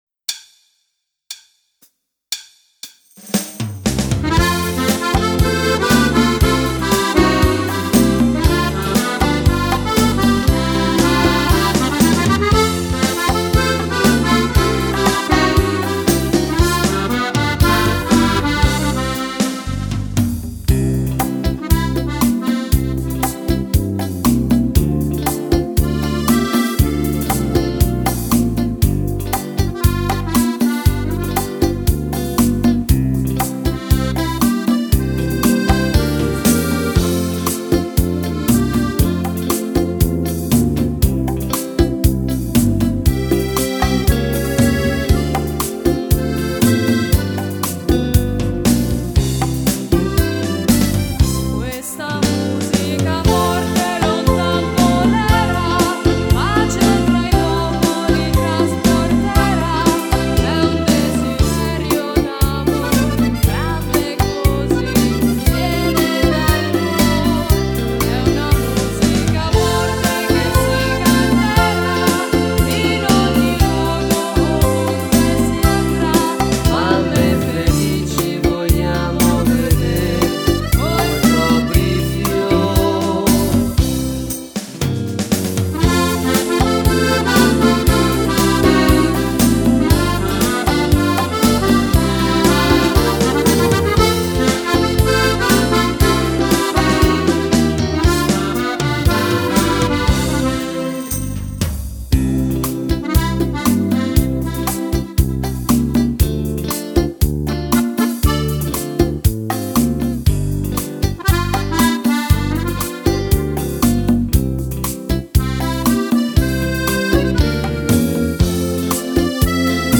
Beguine
Fisarmonica